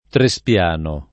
Trespiano [ tre S p L# no ]